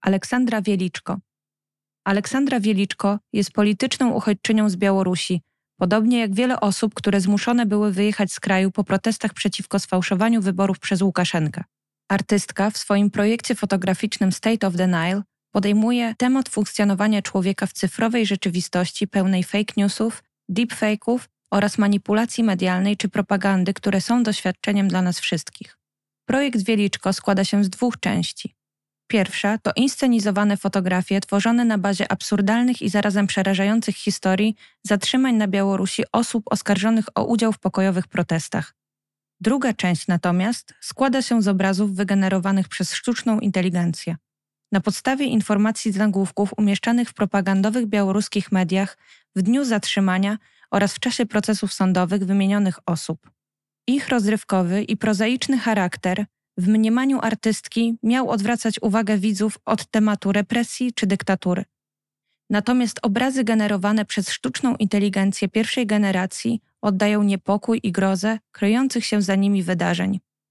czyta: